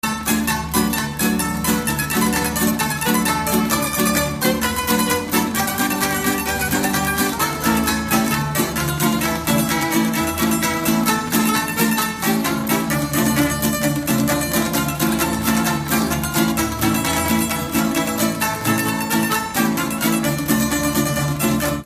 Dallampélda: Hangszeres felvétel
Alföld - Bács-Bodrog vm. - Dávod
), tambura (prím)
), klarinét (B)
), tambura (basszprím)
), tamburakontra
), tamburabőgő
Műfaj: Ugrós
Stílus: 4. Sirató stílusú dallamok